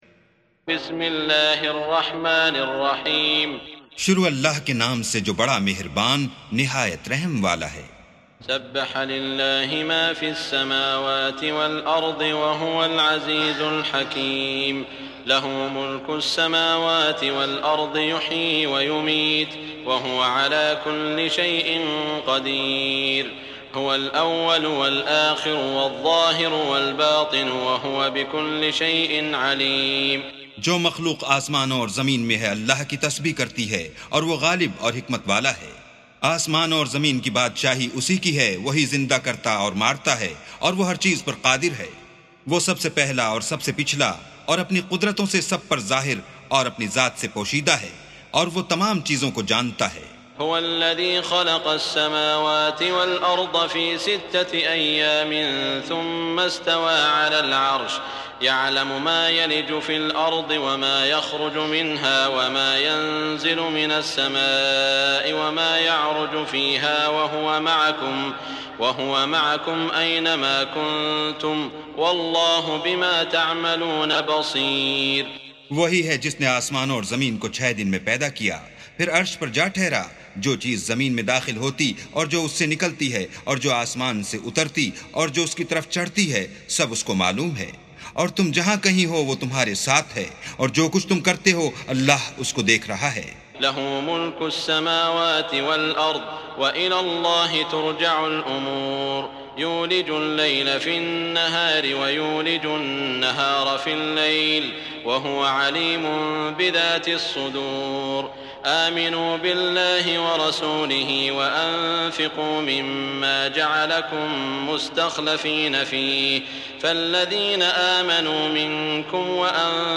سُورَةُ الحَدِيدِ بصوت الشيخ السديس والشريم مترجم إلى الاردو